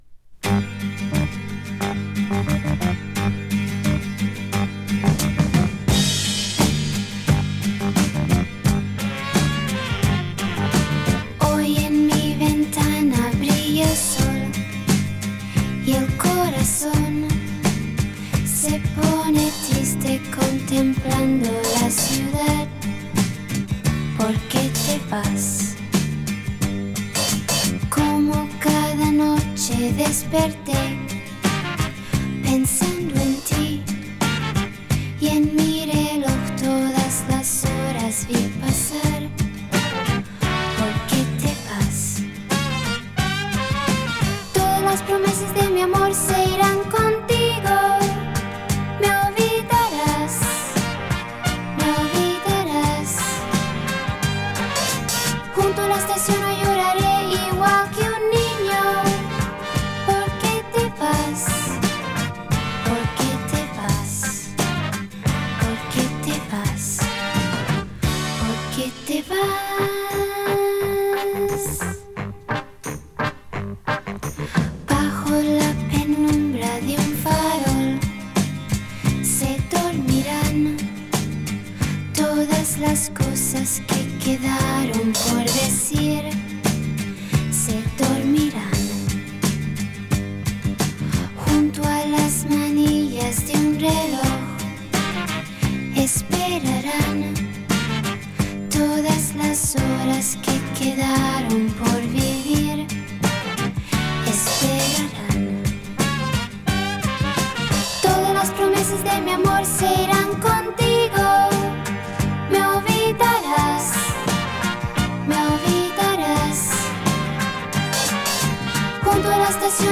Диско, поп-музыка